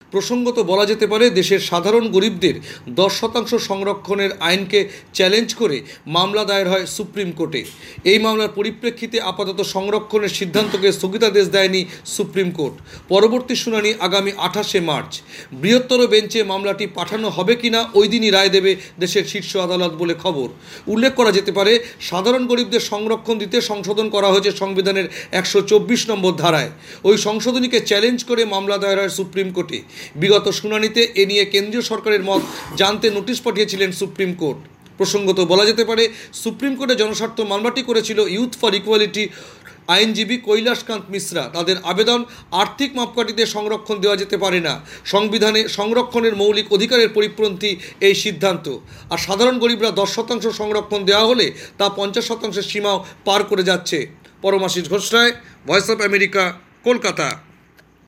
কলকাতা থেকে